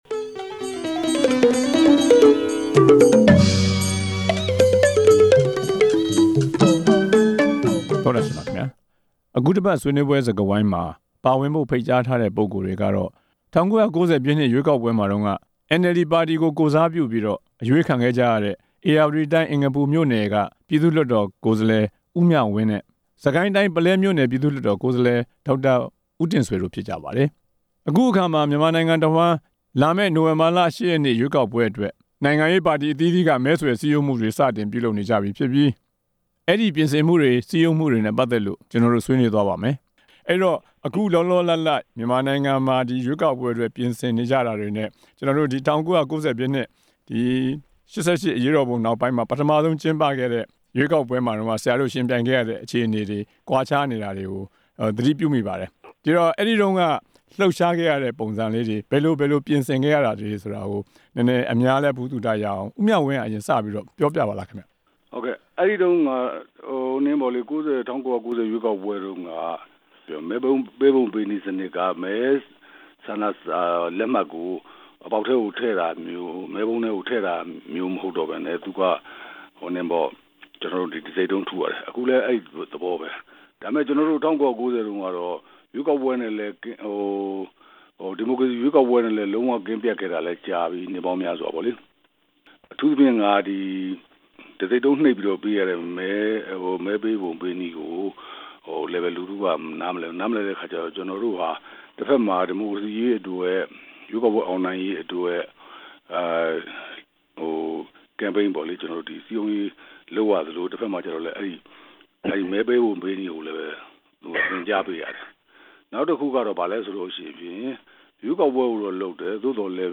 ရွေးကောက်ပွဲအတွက် ပြင်ဆင်မှု အကြောင်း ဆွေးနွေးချက်